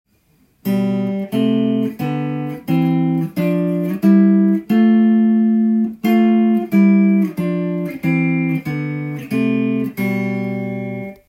6度ハモリでスケールを弾いたTAB譜
５弦始まり